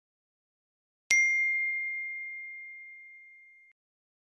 notif.mp3